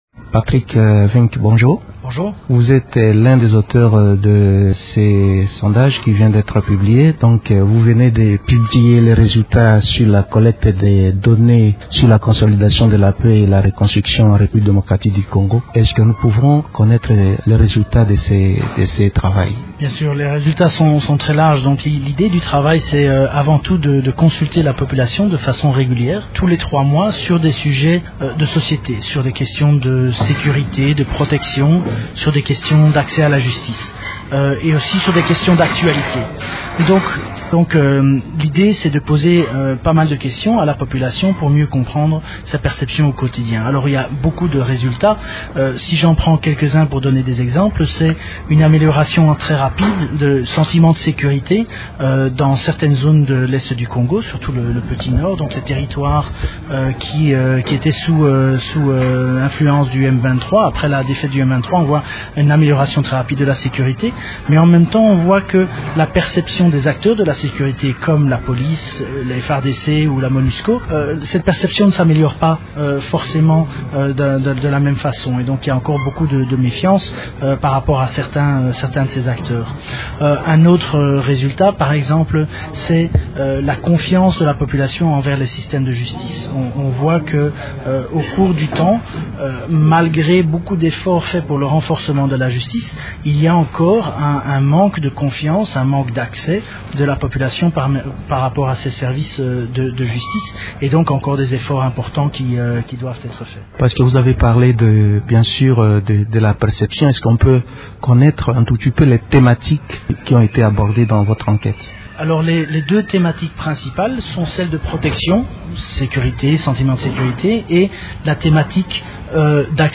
Les résultats d’un sondage présentés mercredi 10 juin à Kinshasa par la Harvard Humanitarian Initiative révèlent que plus de 80% de la population de l’Est de la RDC constatent l’amélioration de la situation sécuritaire. Invité de Radio Okapi de ce matin